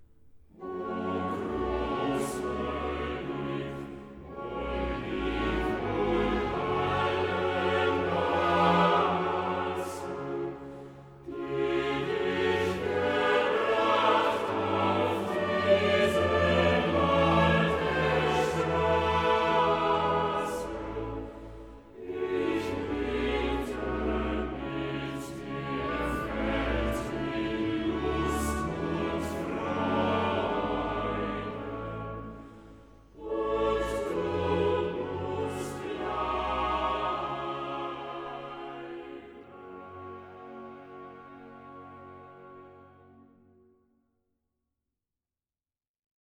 Chorale